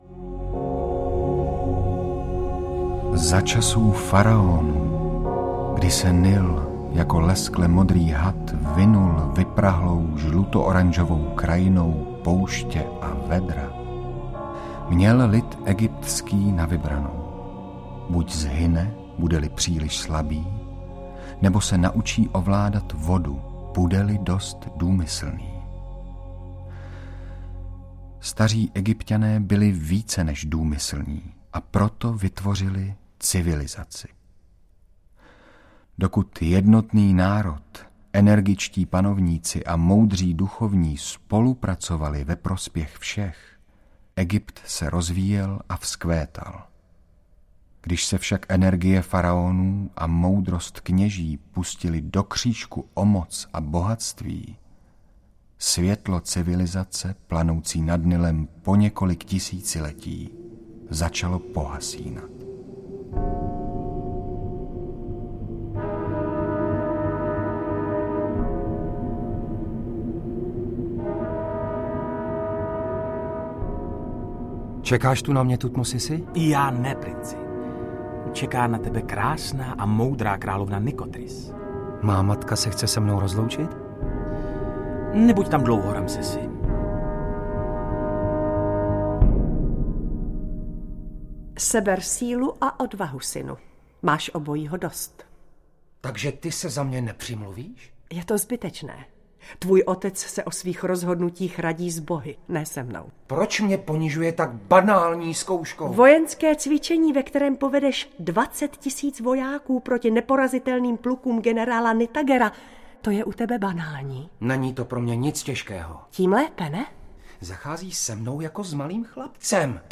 Audiokniha Faraon, kterou napsal Boleslaw Prus. Jde skutečně o velkolepou dramatizaci, kde účinkuje kolem 60 herců.
Ukázka z knihy